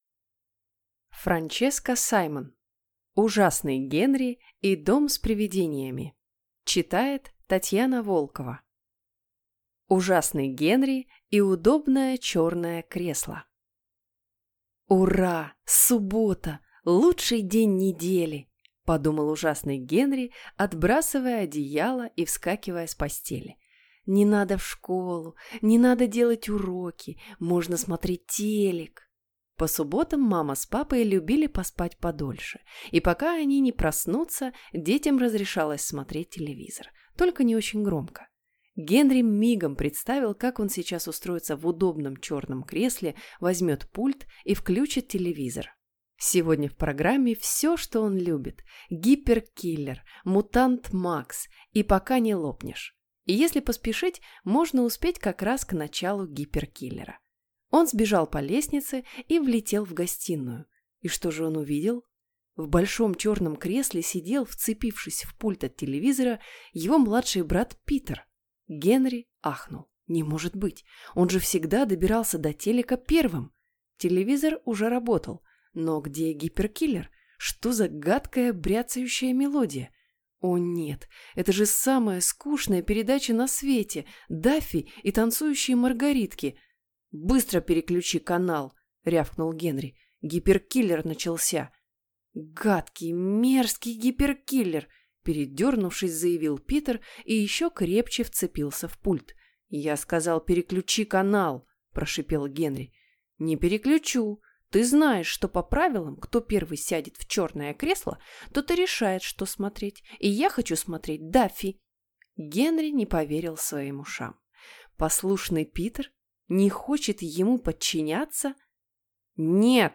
Аудиокнига Ужасный Генри и дом с привидениями | Библиотека аудиокниг